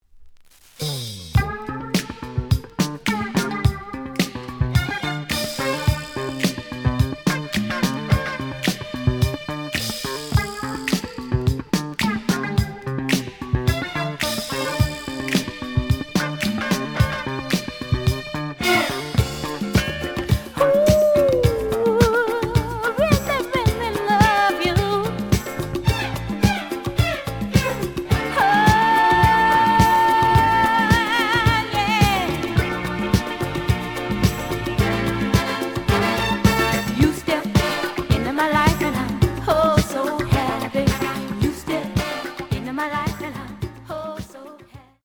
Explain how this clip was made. The audio sample is recorded from the actual item. Looks good, but slight noise on parts of both sides.